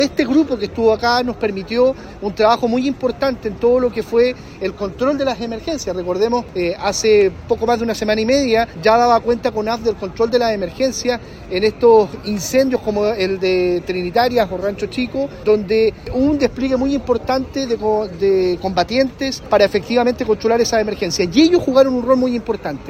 Por ello, se llevó a cabo una ceremonia en agradecimiento y despedida de los brigadistas. Al respecto, el delegado presidencial del Bío Bío, Eduardo Pacheco, destacó el aporte del contingente mexicano en la temporada crítica.
cuna-delegado-presidencial-despedida-brigadistas.mp3